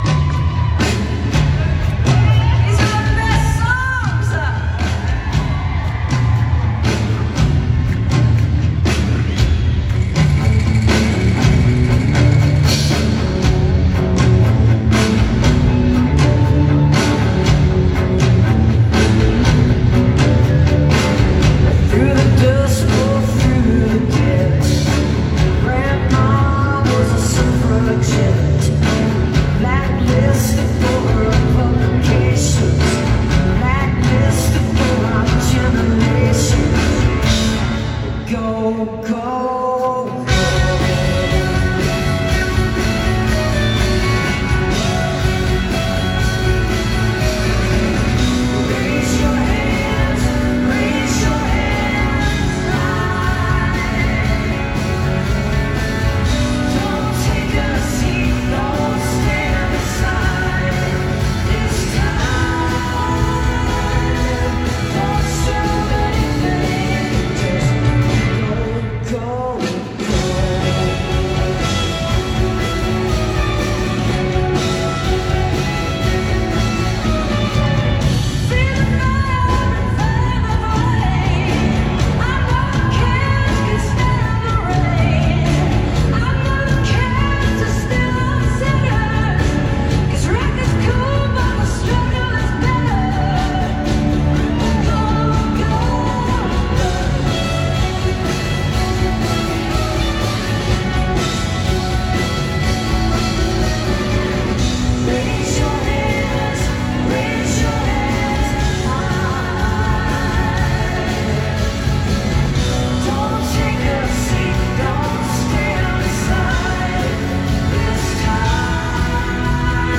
facebook live stream capture